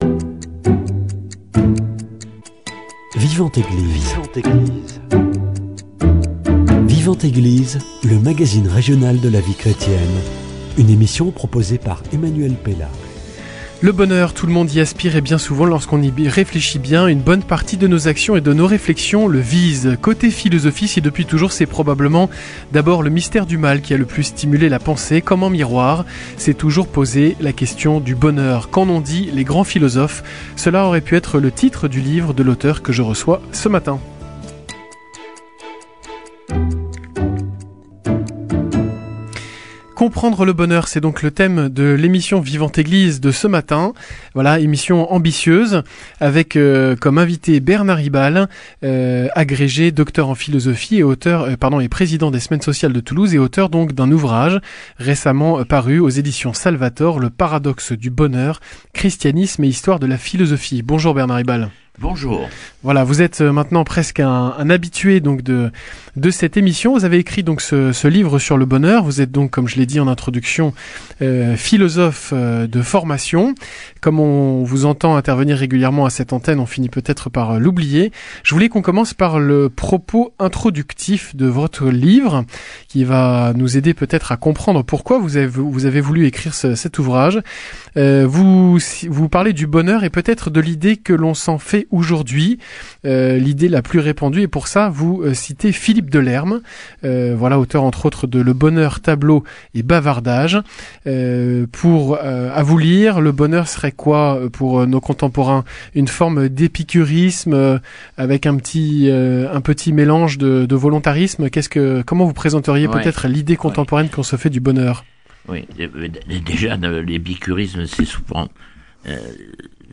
Qu’ont dit les grands philosophes de cette question ? C’est l’objet du livre de mon invité de ce matin. Coup de zoom sur trois figures : René Descartes, Jean-Paul Sartre et Jésus-Christ.